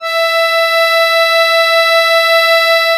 MUSETTESW.12.wav